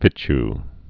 (fĭch) also fitch·et (-ĭt)